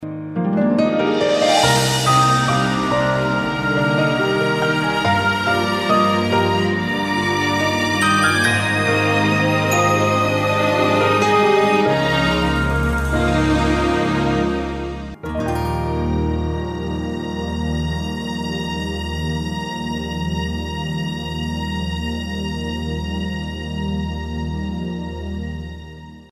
地摊货批发网 » 配音 » 背景音乐 » 背景音乐分类 » 婚礼背景音乐 » 正文
【简介】： 浪漫、典雅婚礼